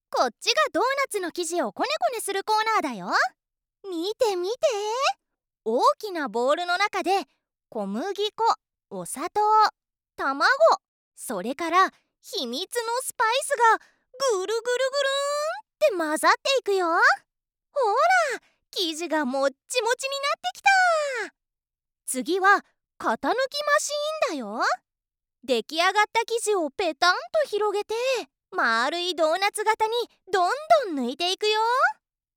元CATVアナウンサーが温かみのある爽やかな声をお届けします。
キャラクター風、子供向け